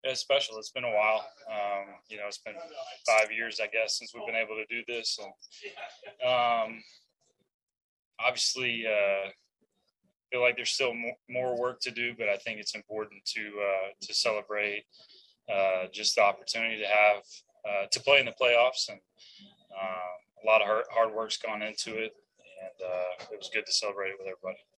Giants catcher, Buster Posey, acknowledged that it has been a while since the team made it to the postseason. Posey also noted the amount of work the team put in to get there.